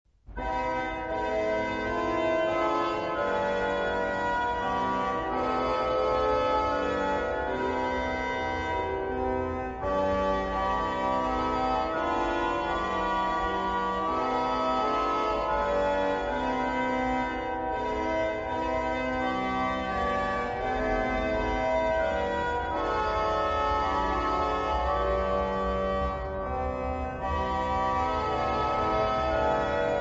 Personaggi e interpreti: organo ; Leonhardt, Gustav